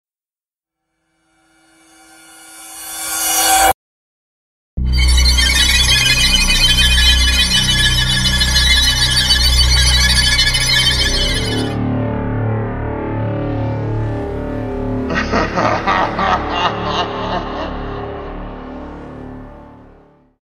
scary-monster-sound-effects-for-kids-cartoon-no-copyright.mp3